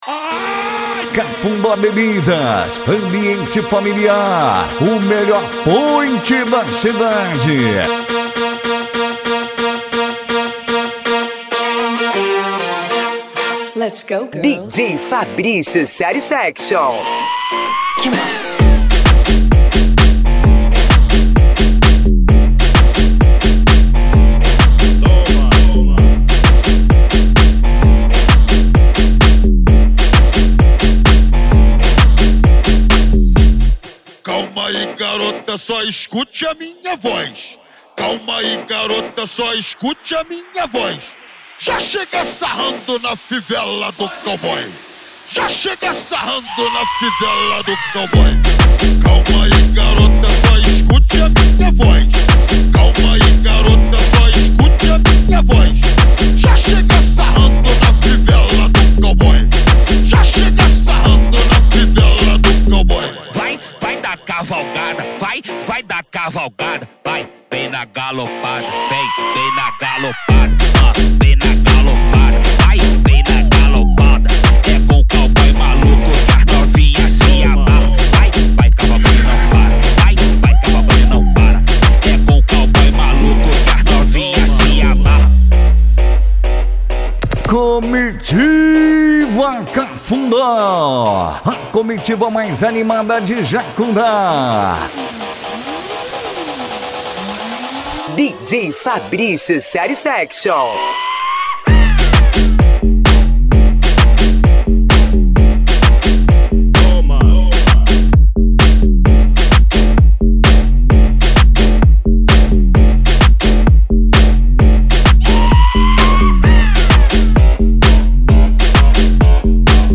Country Music
Funk
Funk Nejo
SERTANEJO